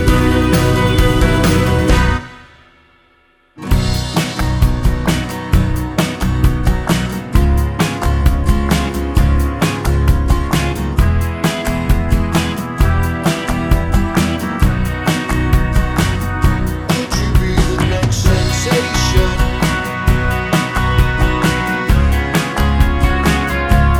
One Semitone Down Jazz / Swing 4:09 Buy £1.50